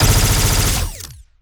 Added more sound effects.
GUNAuto_Plasmid Machinegun B Burst_05_SFRMS_SCIWPNS.wav